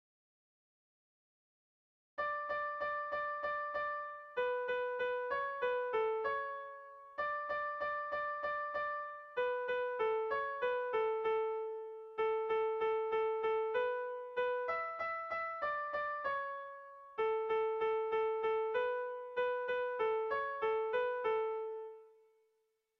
Kontakizunezkoa
Zortziko txikia (hg) / Lau puntuko txikia (ip)
A1A2B1B2